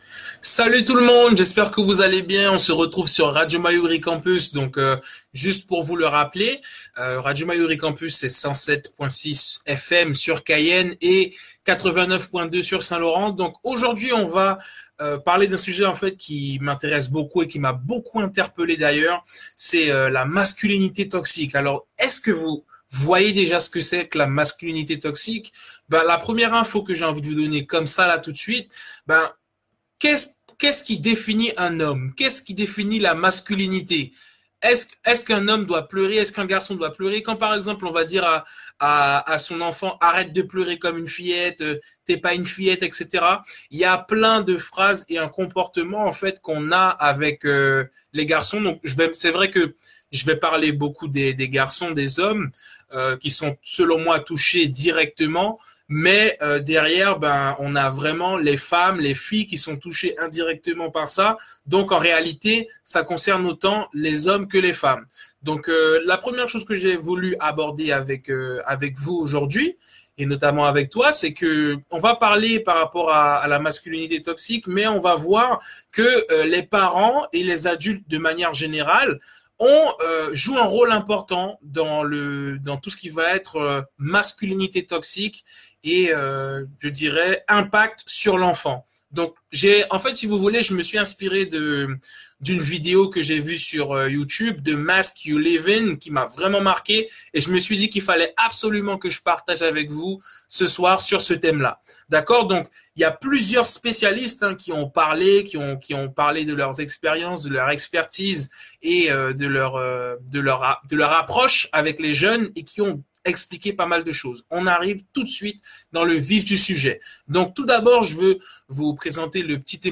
dans son facebook live mensuel sur Radio Mayouri Campus